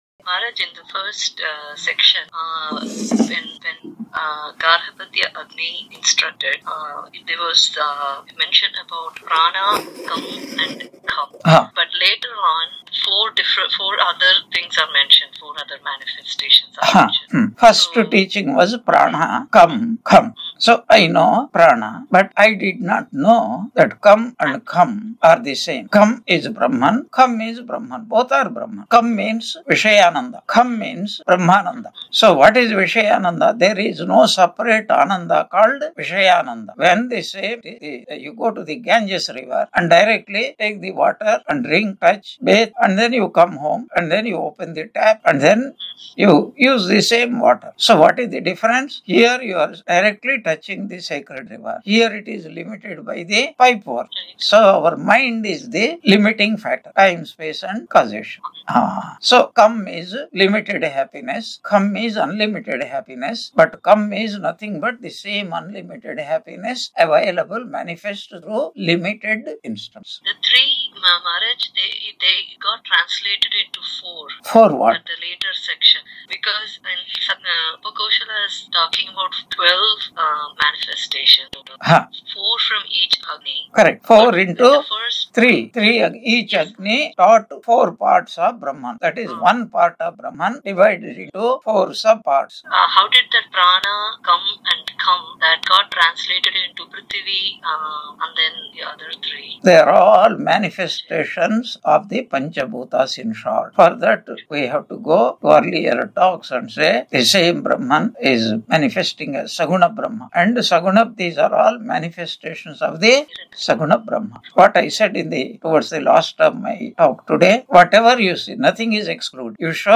Chandogya Upanishad 4.14 Lecture 143 on 04 October 2025 Q&A